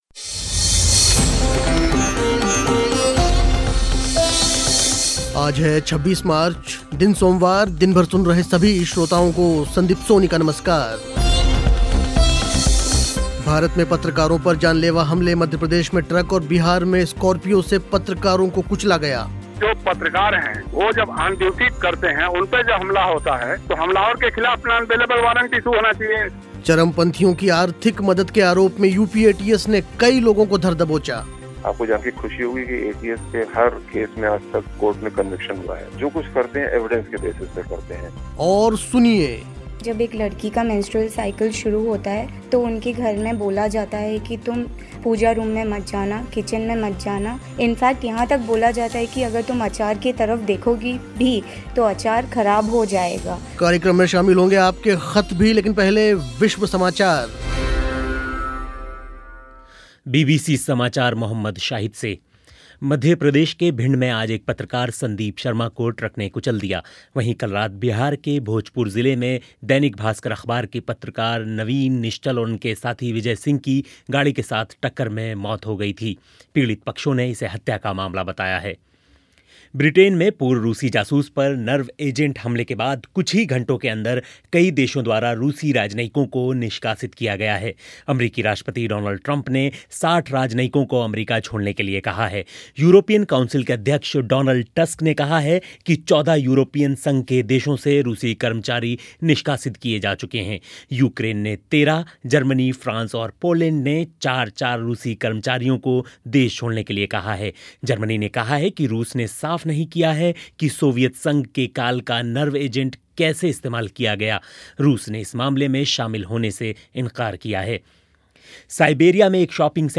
सुनिए अंधविश्वास और कुरीतियों पर दक्षिण भारतीय लड़कियों के धारदार तर्क. कार्यक्रम में शामिल होंगे आज आपके ख़त भी.